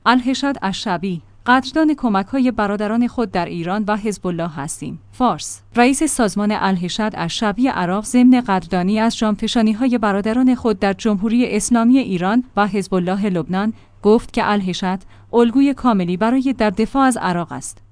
ایسنا/ رئیس الحشد الشعبی عراق در مراسم یادبود سرداران مقاومت تأکید کرد که ایران و لبنان فداکاری‌های بزرگی انجام دادند.